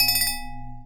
chime_bell_09.wav